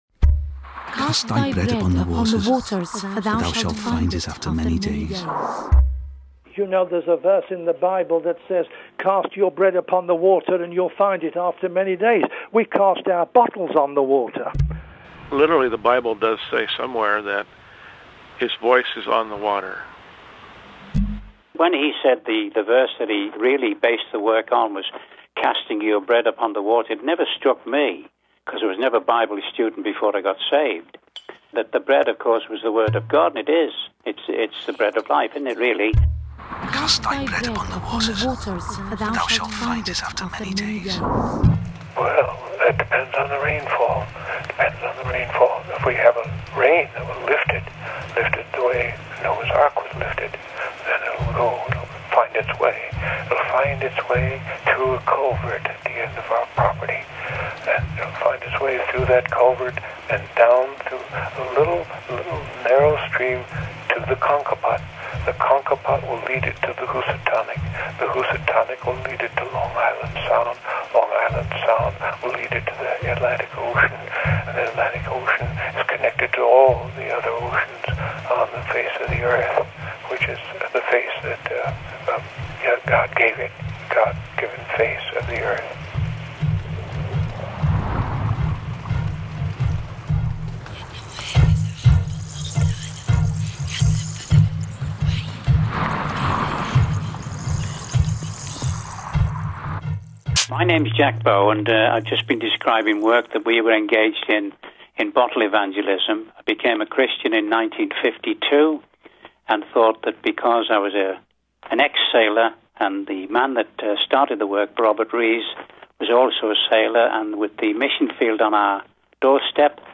A hybrid documentary with fictive intervention, floating around in the slipstreams of bottle evangelism.